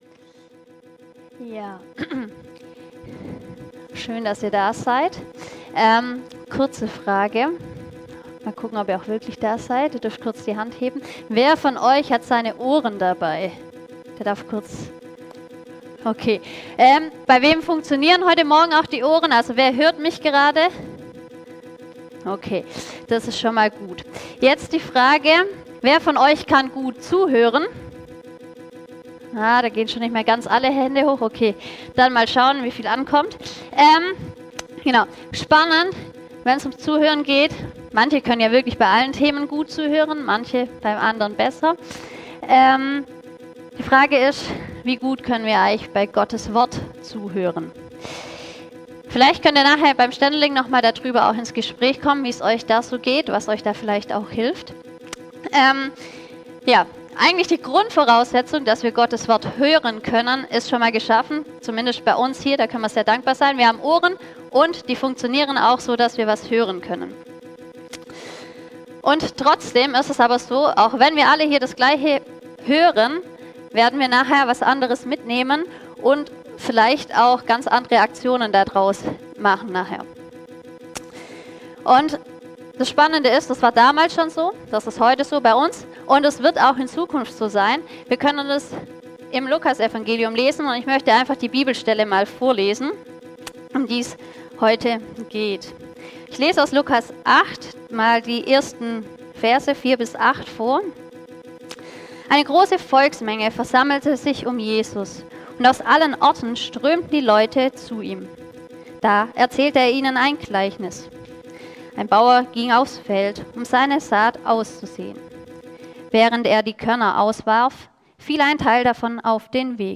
Predigten für die ganze Familie